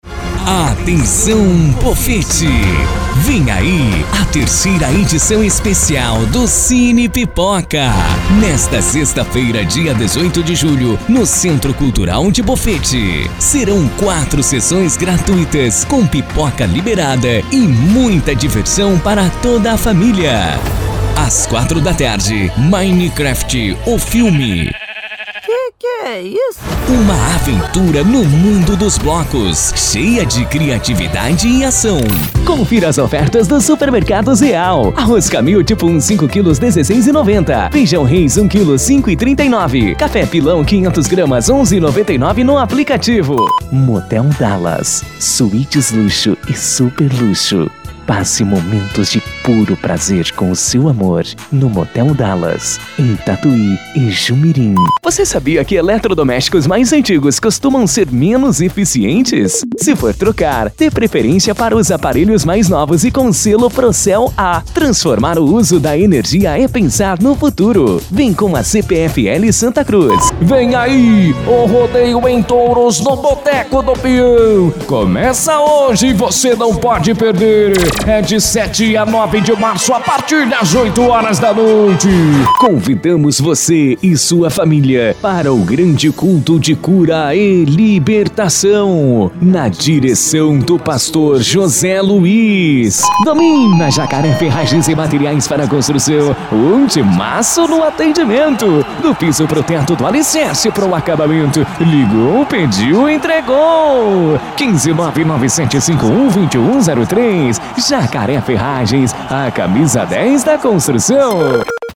Padrão
Impacto
Animada
Caricata